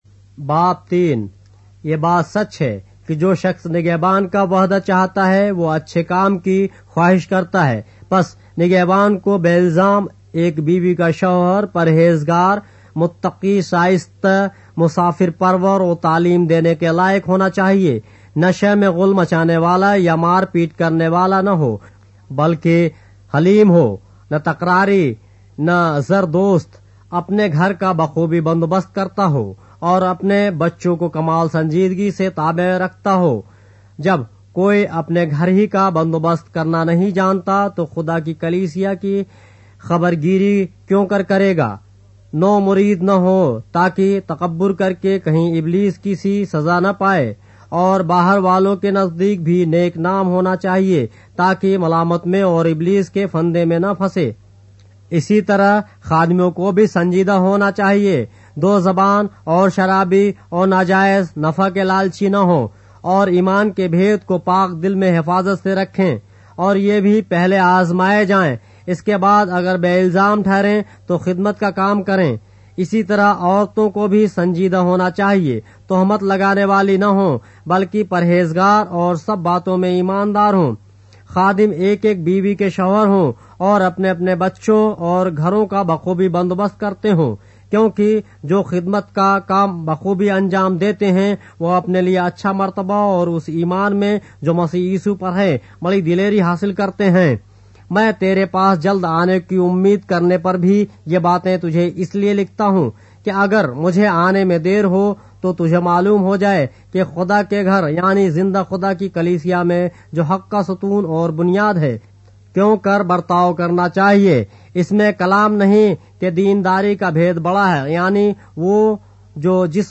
اردو بائبل کے باب - آڈیو روایت کے ساتھ - 1 Timothy, chapter 3 of the Holy Bible in Urdu